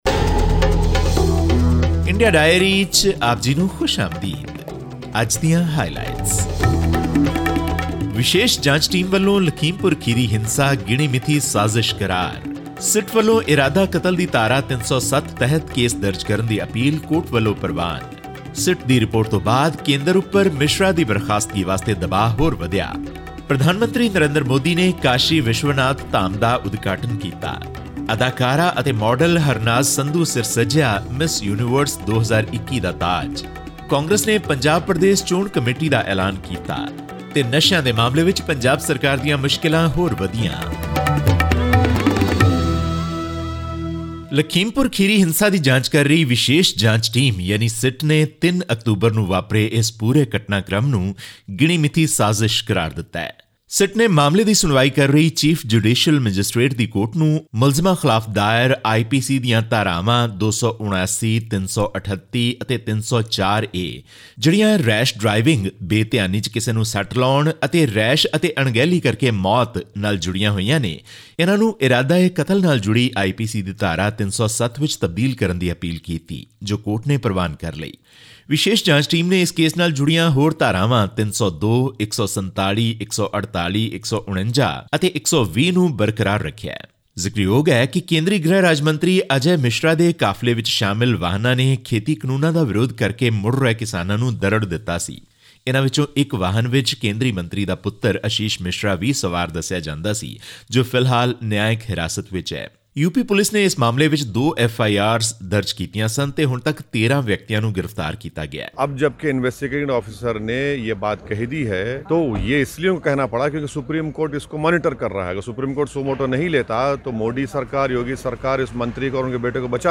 The Special Investigation Team probing the Lakhimpur Kheri incident of October 3 has pointed out in its application before the Chief Judicial Magistrate (CJM) that the incident was well-planned and a deliberate act. All this and more in our weekly news segment from India.